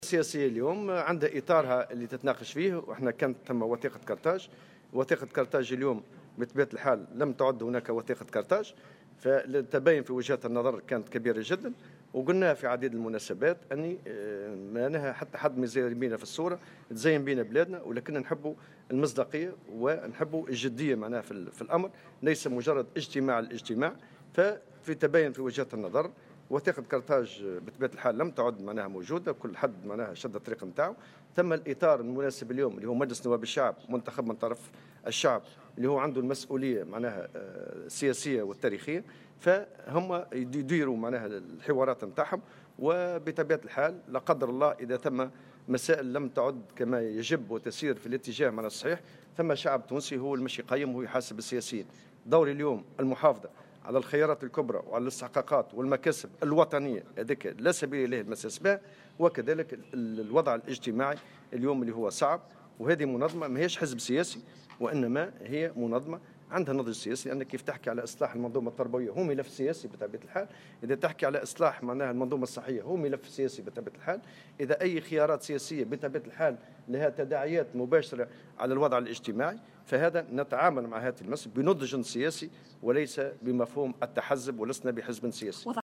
وأضاف في تصريح اليوم السبت، اثر لقاء جمعه برئيس مجلس نواب الشعب، محمد الناصر أن للبرلمان المُنتخب مسؤولية سياسية وتاريخية، وفق تعبيره.